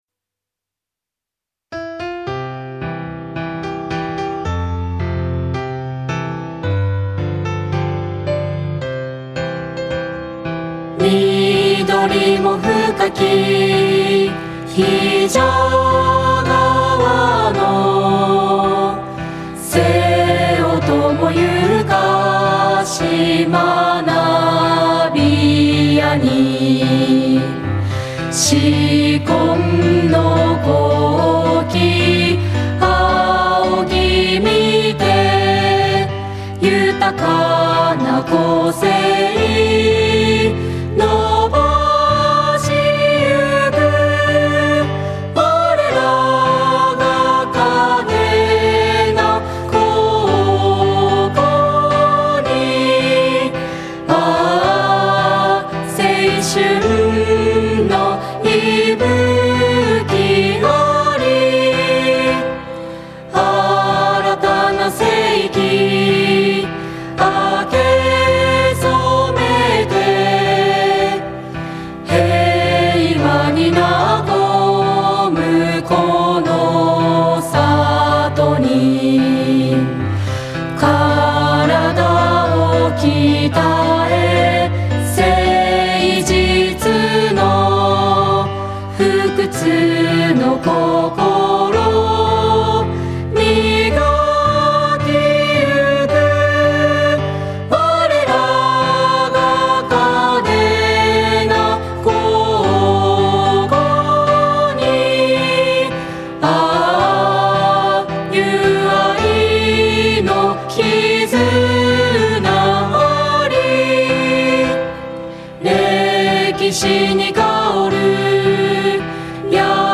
３．校　歌